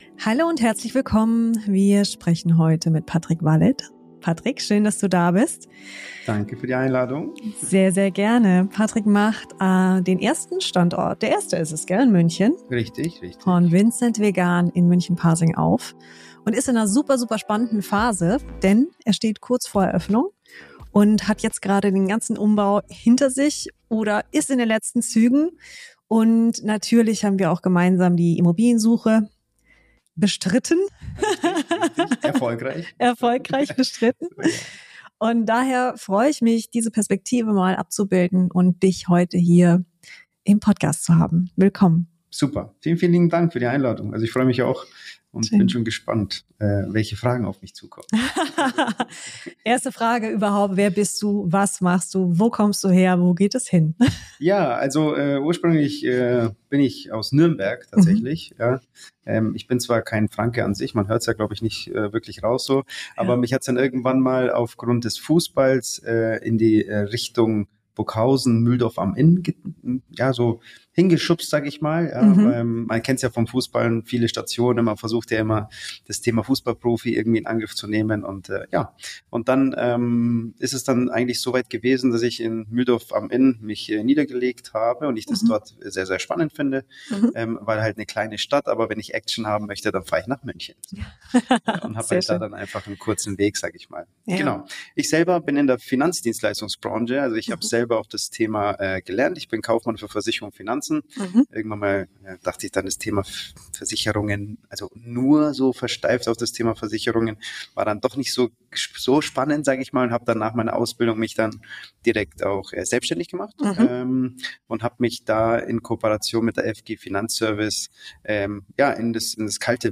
im Interview | Franchising als Fundament für die eigene Selbständigkeit ~ GewerbeRaum: Investieren & Betreiben in München | Gewerbeimmobilien | Einzelhandel & Gastronomie Podcast